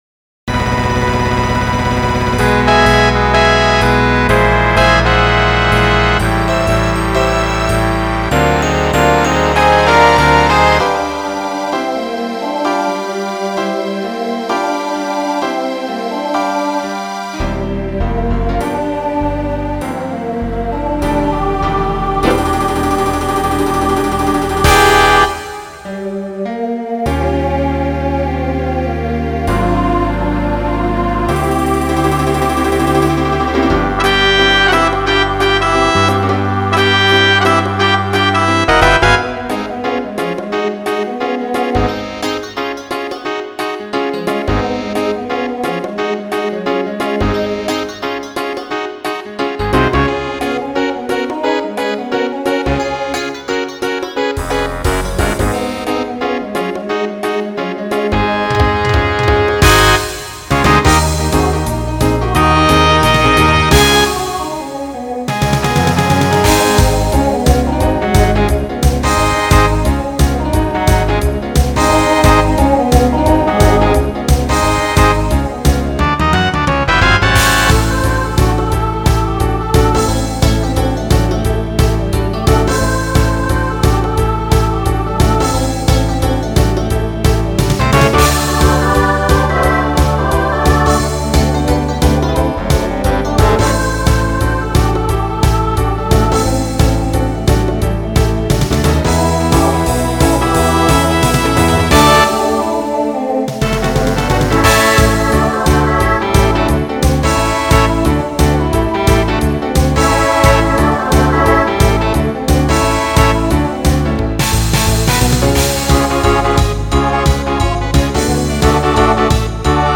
Voicing SATB Instrumental combo Genre Broadway/Film
Show Function Opener